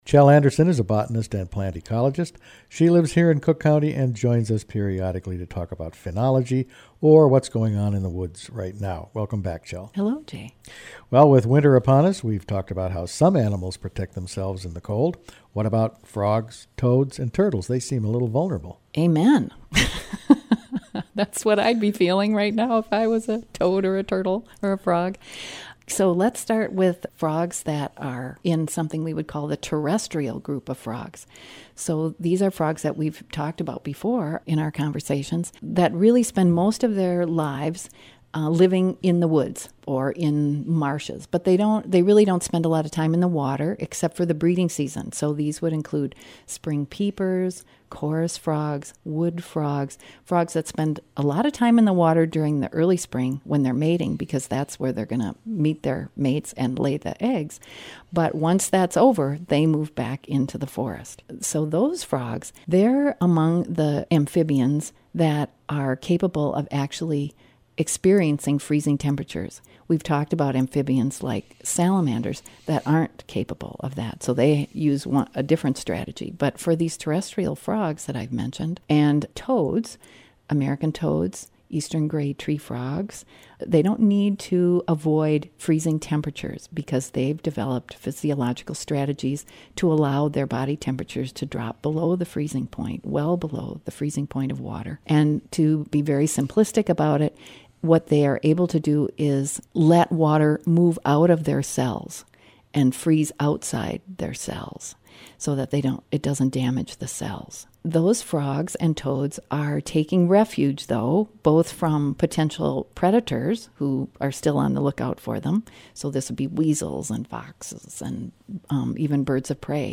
Frogs face the freeze in different ways | WTIP North Shore Community Radio, Cook County, Minnesota